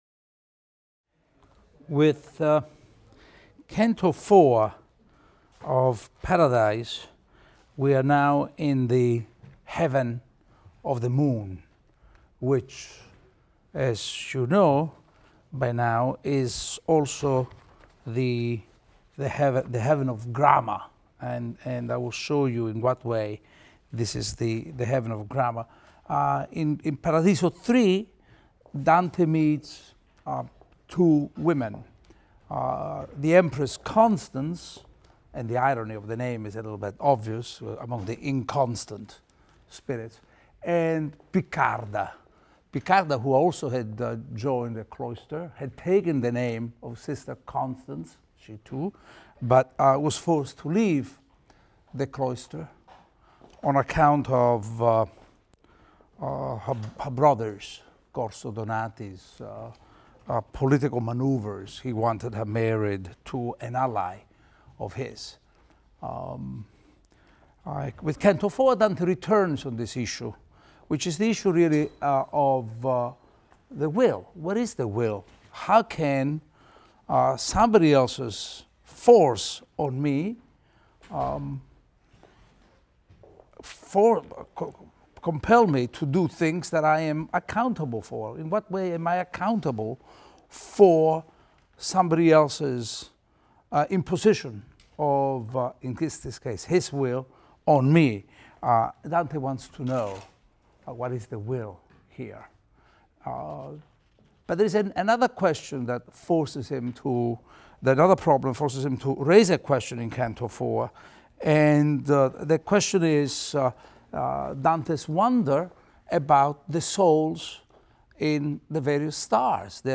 ITAL 310 - Lecture 17 - Paradise IV, VI, X | Open Yale Courses